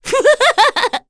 Cecilia-Vox_Happy3_kr.wav